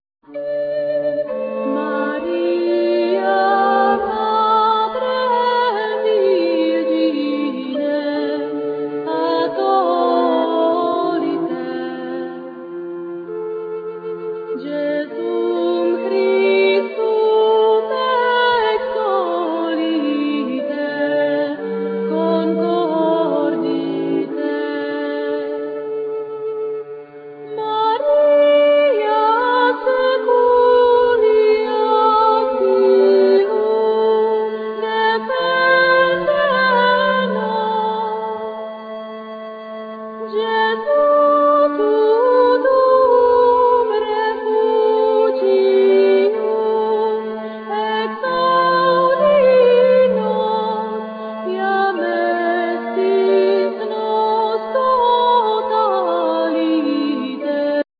Flute,Percussions,Gittern,Vocals,Shawm,Harp
Gittern,Saz,Tarabuka,Vocals,Hurdy gurdy
Shawm,Bagpipes,Harp
Santur,Fiddle,Davul,Percussions